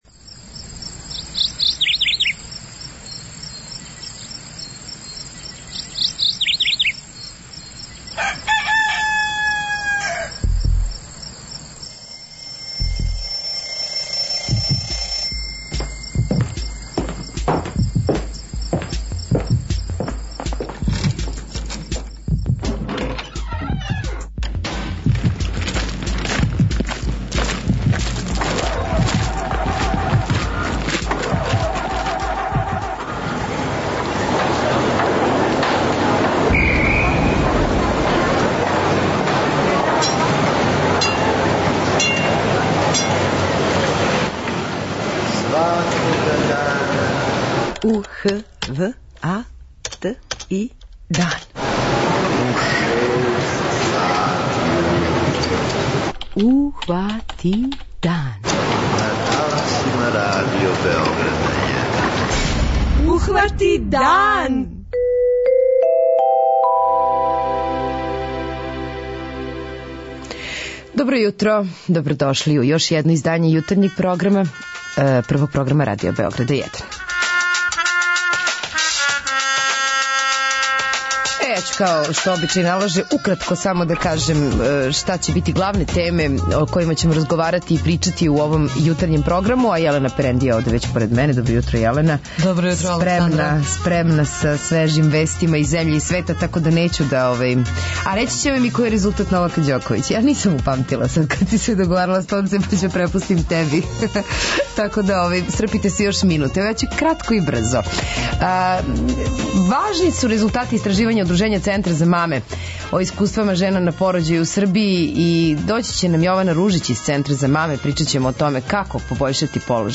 преузми : 43.14 MB Ухвати дан Autor: Група аутора Јутарњи програм Радио Београда 1!